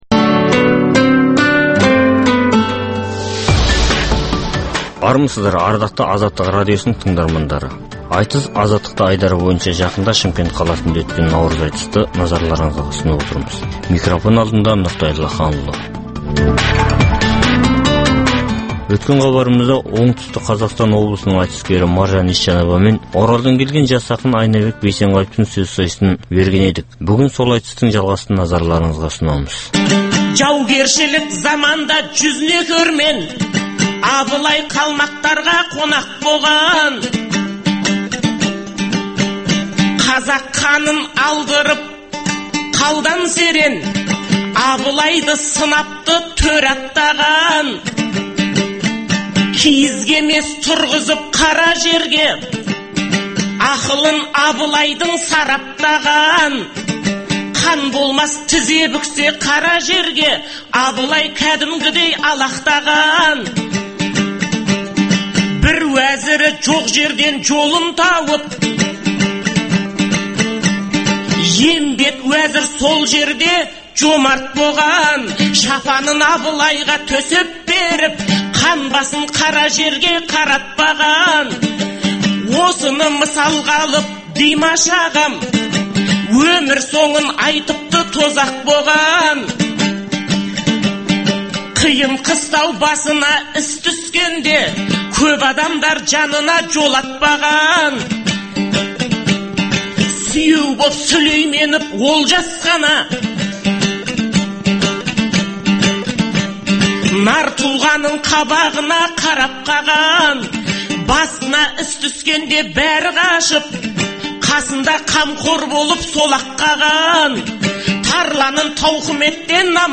Айтыс - Азаттықта
Қазақстанда әр уақытта өткізілетін ақындар айтысының толық нұсқасын ұсынамыз.